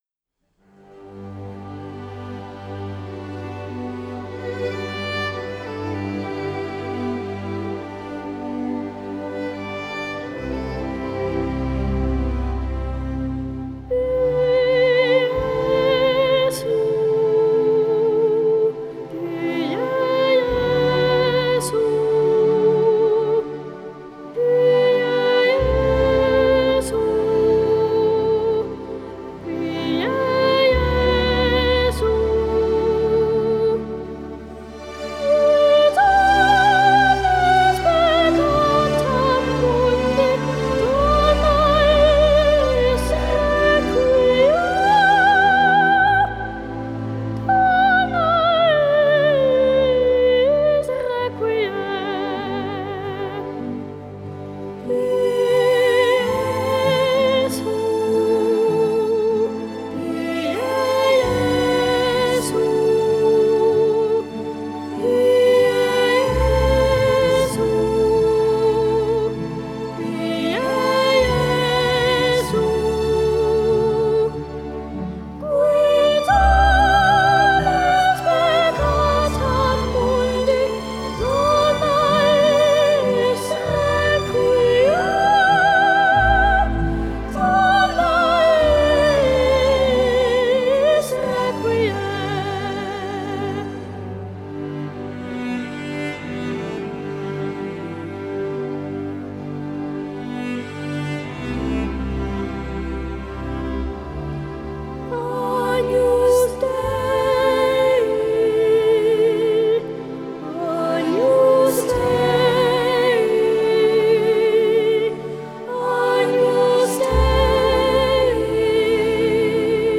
Genre: Classical, Opera